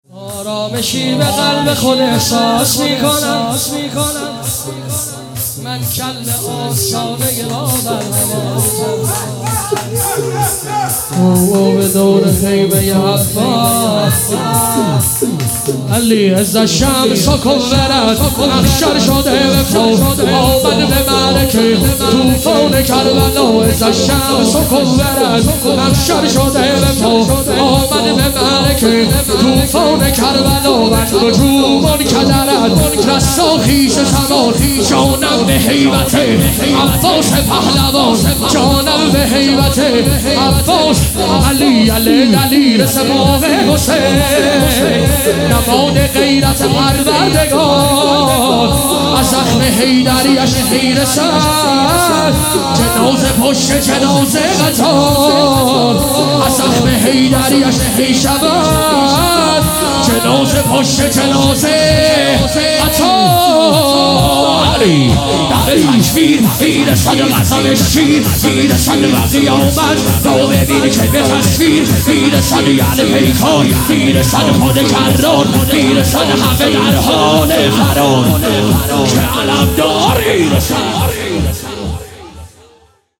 شهادت حضرت مسلم ابن عقیل (ع)